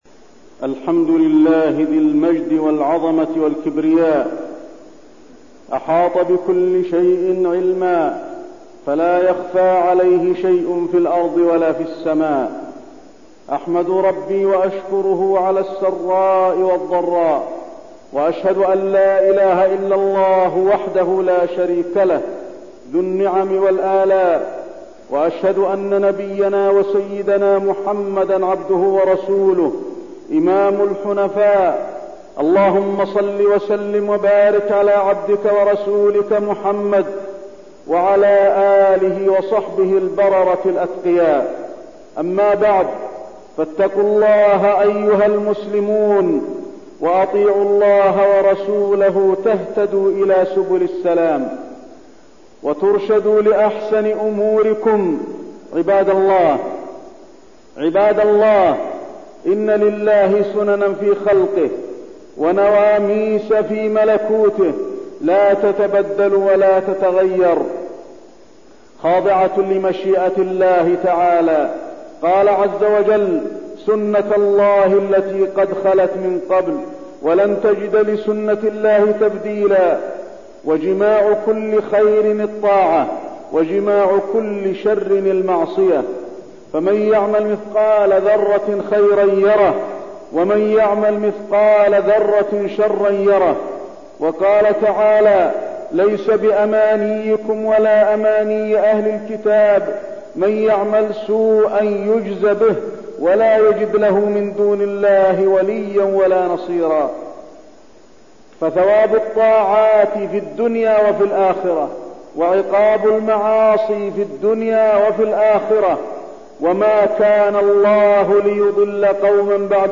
تاريخ النشر ٢٥ ربيع الثاني ١٤١٢ هـ المكان: المسجد النبوي الشيخ: فضيلة الشيخ د. علي بن عبدالرحمن الحذيفي فضيلة الشيخ د. علي بن عبدالرحمن الحذيفي ضرورة التمسك بالدين